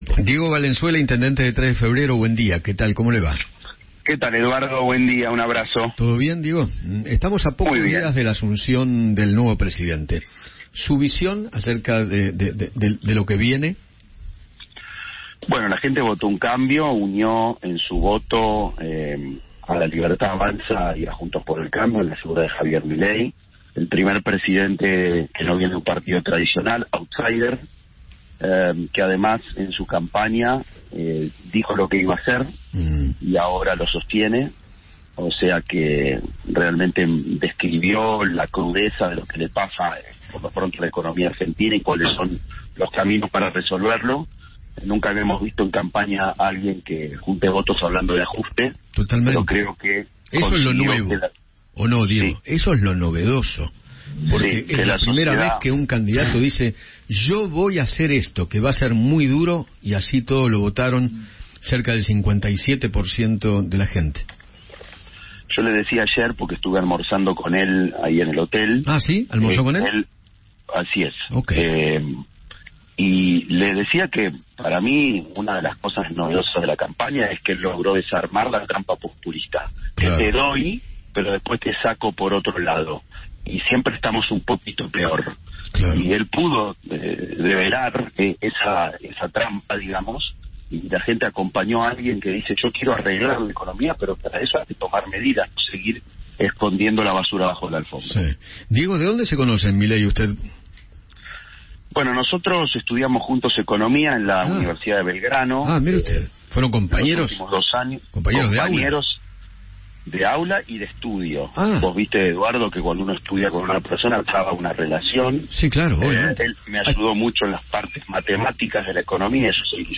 Diego Valenzuela, intendente de Tres de Febrero, conversó con Eduardo Feinmann sobre la futura gestión de Javier Milei.